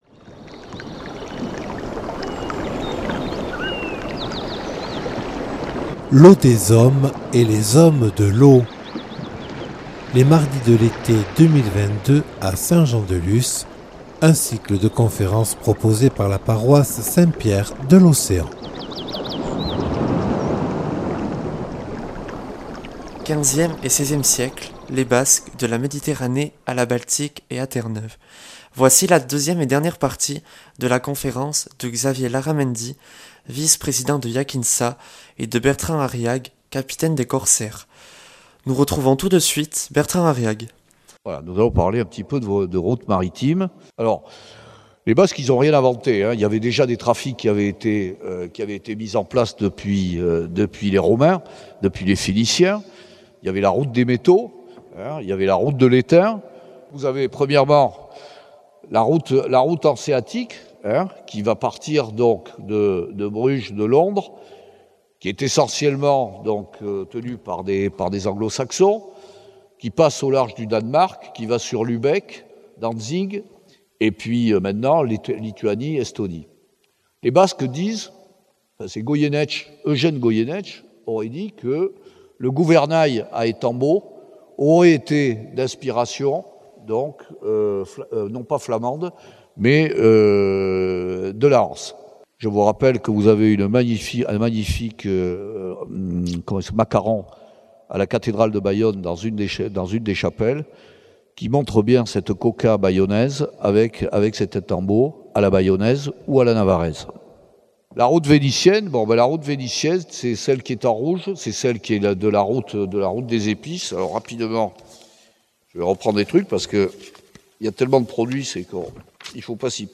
Seconde partie d’une conférence